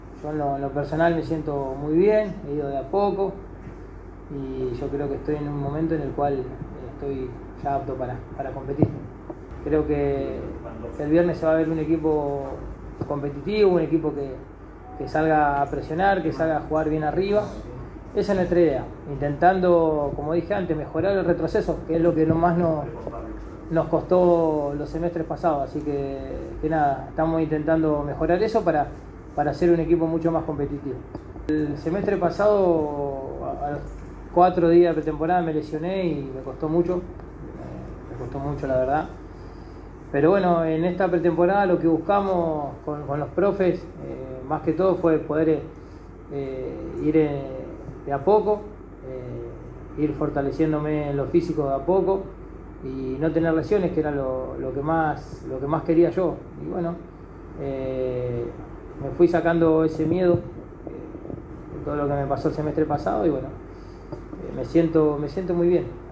En la antesala al inicio de la nueva temporada del fútbol argentino, Emiliano Vecchio brindó una conferencia de prensa. Entre otras cosas, el capitán de Central se refirió a los objetivos del plantel  y analizó su rol en el club de Arroyito.